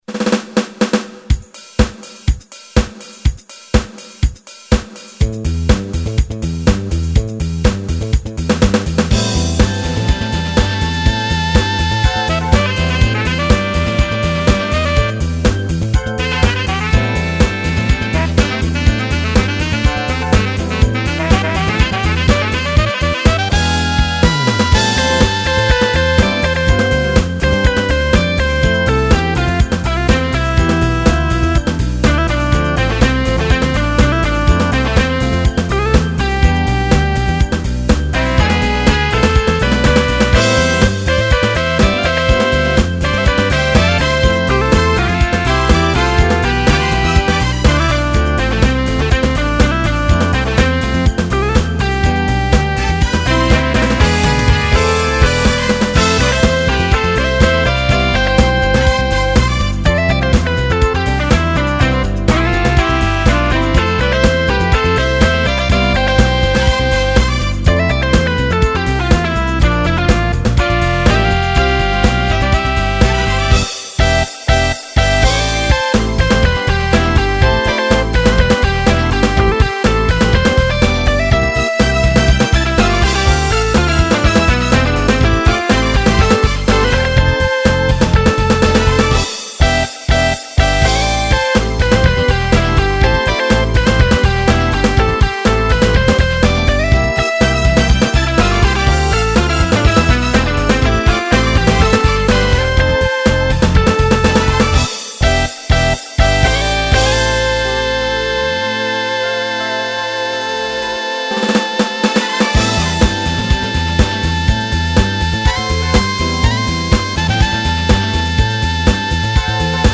베이스 리듬은 차용에 가까울 정도...! OTL
Sound Module : Roland Sound Canvas 88 Pro